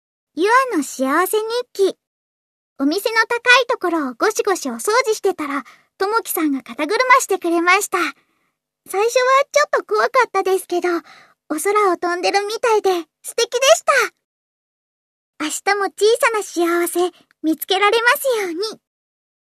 日替わりボイス「ゆあの幸せ日記」を公開